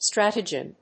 strategian.mp3